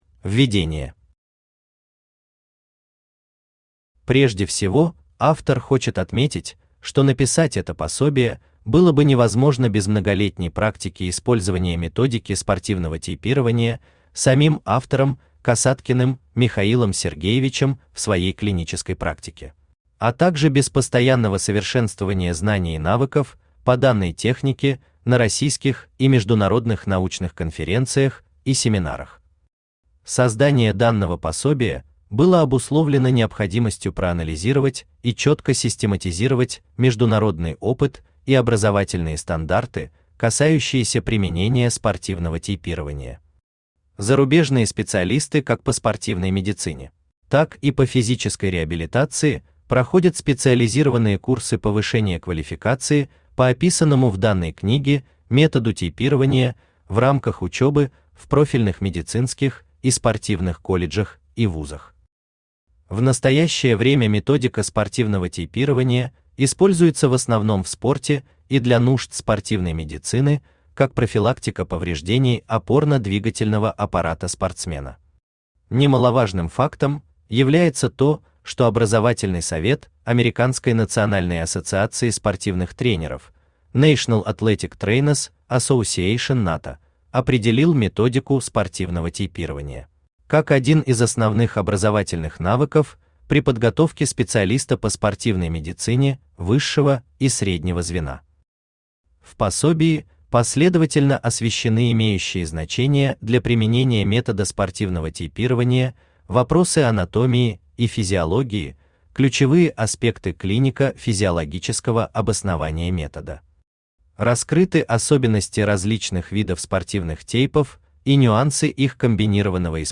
Аудиокнига Основы спортивного тейпирования | Библиотека аудиокниг
Aудиокнига Основы спортивного тейпирования Автор Михаил Сергеевич Касаткин Читает аудиокнигу Авточтец ЛитРес.